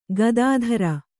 ♪ gadādhara